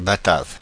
Ääntäminen
Synonyymit Néerlandais Hollandais Ääntäminen Paris: IPA: [ba.tav] France (Paris): IPA: /ba.tav/ Haettu sana löytyi näillä lähdekielillä: ranska Käännöksiä ei löytynyt valitulle kohdekielelle.